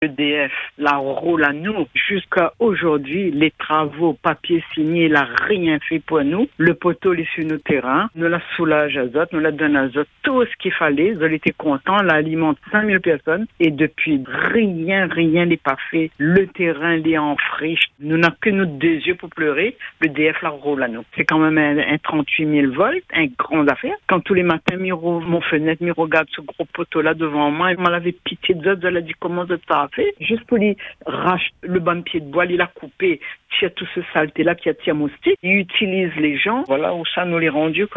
Écoutez son témoignage complet dans notre podcast.